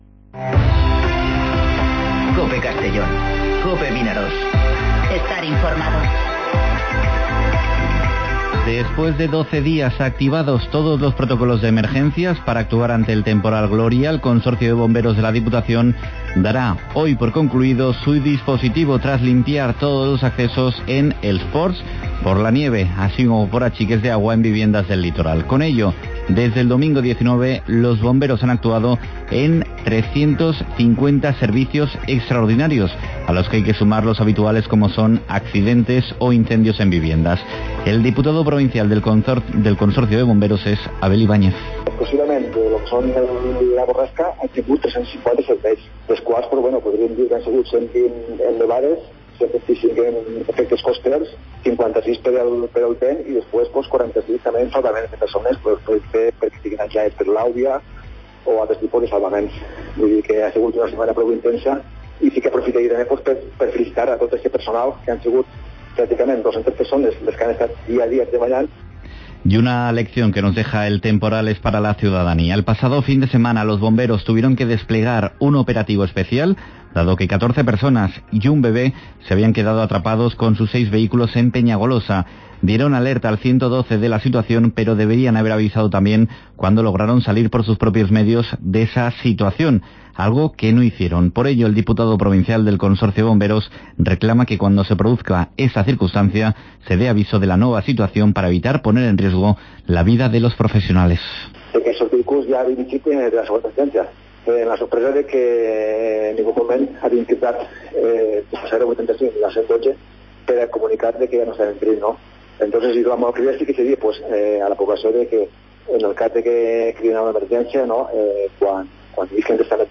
Informativo Mediodía COPE en Castellón (30/01/2020)